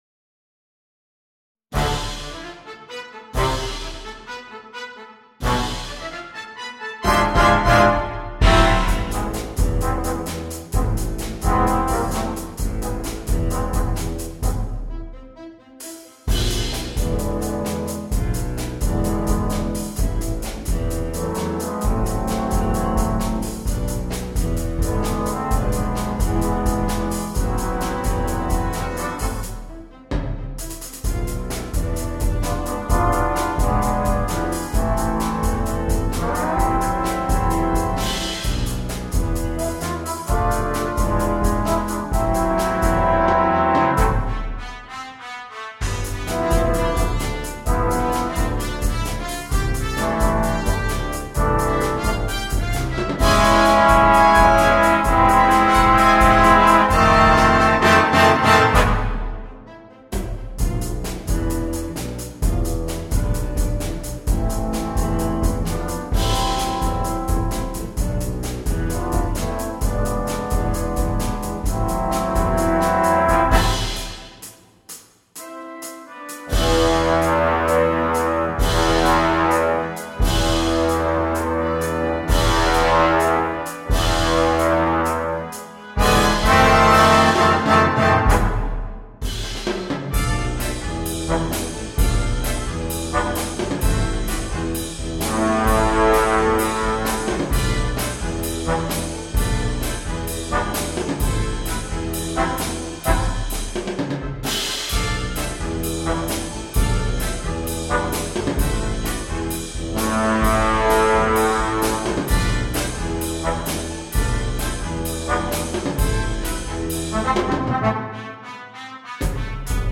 для биг-бэнда